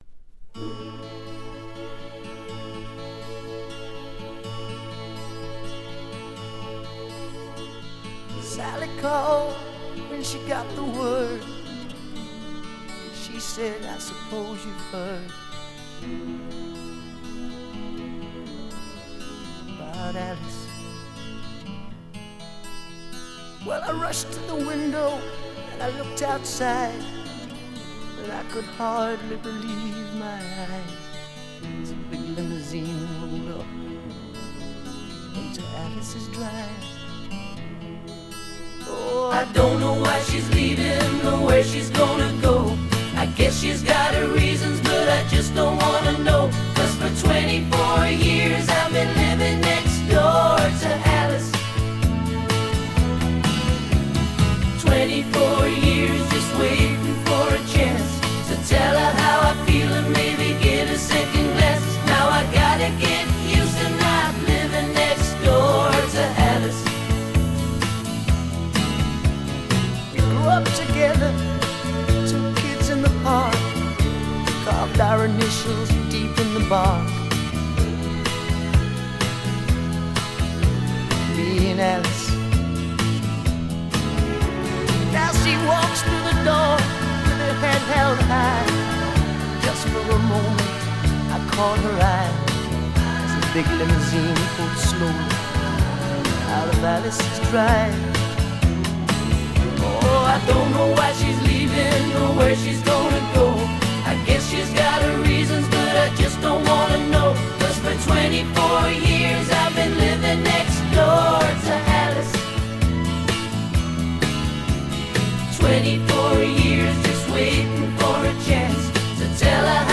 Жанр: Rock, Pop Rock